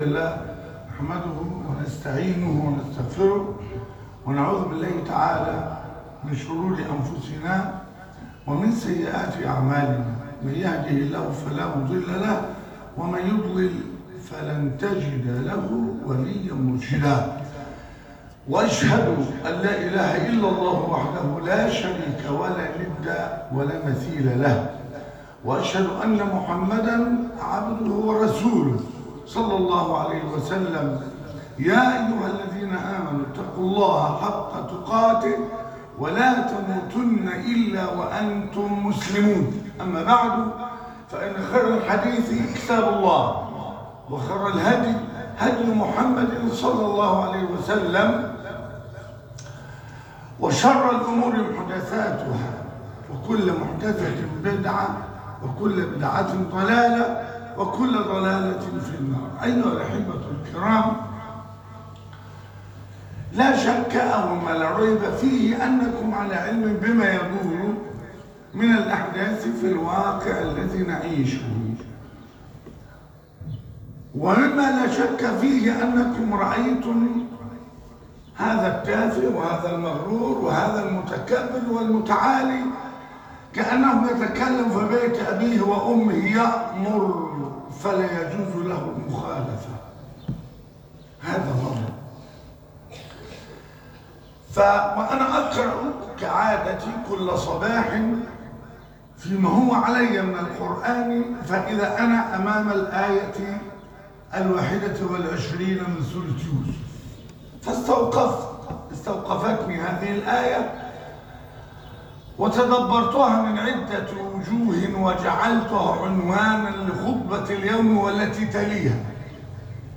والله غالب على أمره - الخطبة الأولى